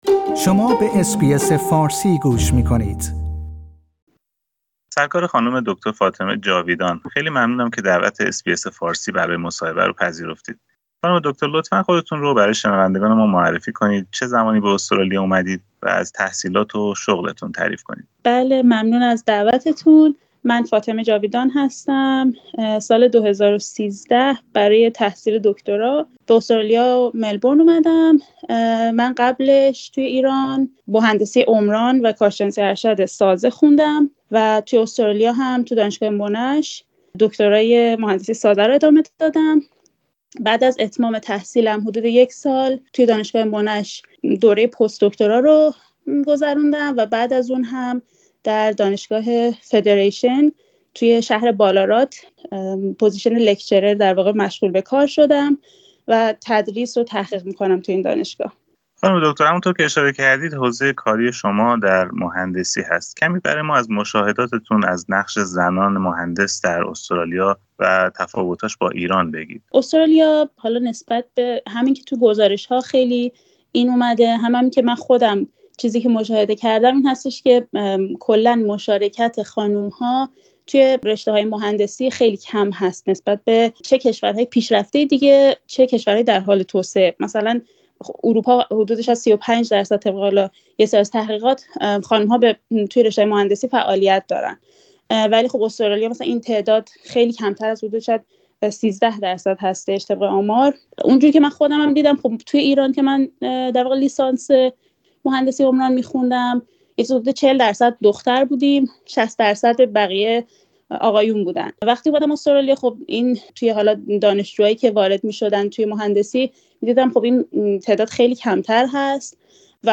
در گفتگویی با اس بی اس فارسی